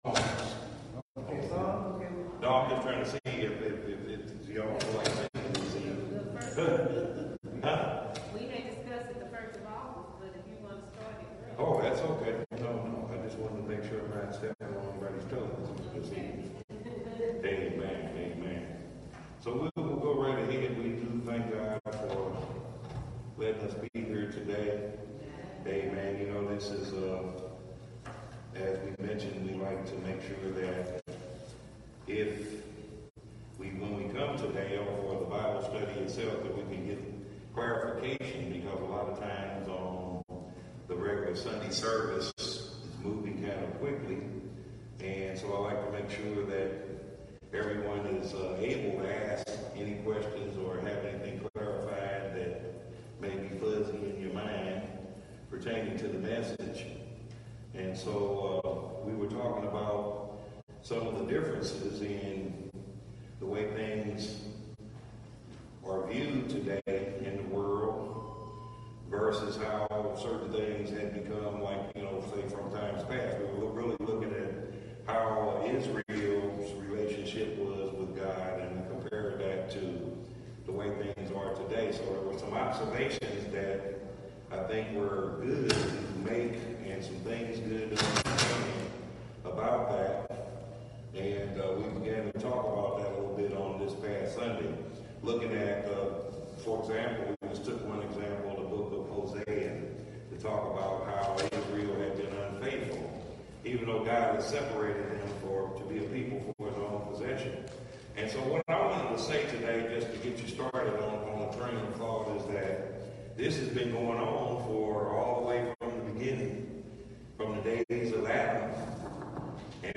printWednesday night Bible Study is a clarification of our Sunday message. This session teaches more details from Sunday Morning’s message.